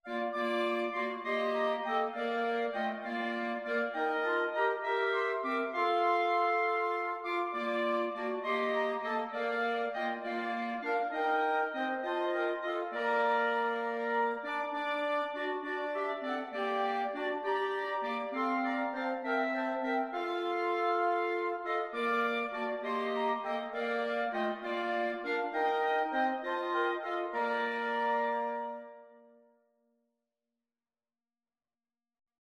Christmas Christmas Clarinet Quartet Sheet Music It Came Upon the Midnight Clear
Bb major (Sounding Pitch) C major (Clarinet in Bb) (View more Bb major Music for Clarinet Quartet )
6/8 (View more 6/8 Music)
Clarinet Quartet  (View more Intermediate Clarinet Quartet Music)
Traditional (View more Traditional Clarinet Quartet Music)